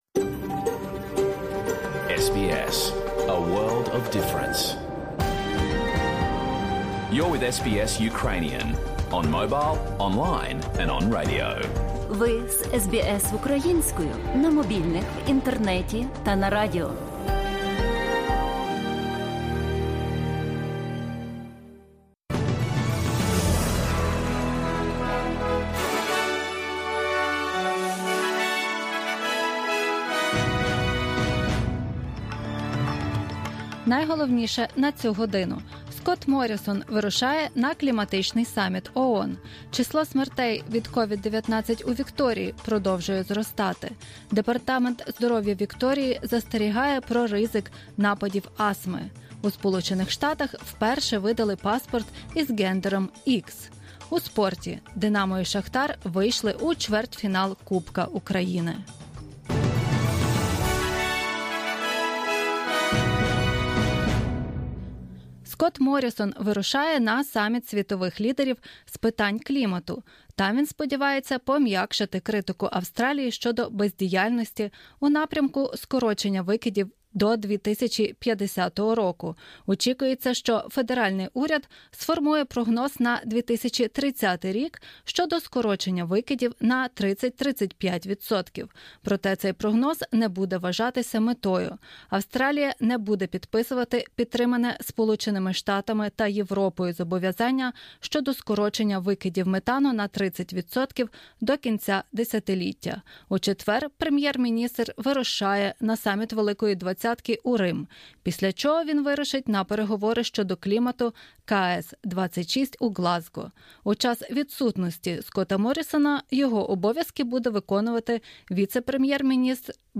SBS новини українською - 28 жовтня 2021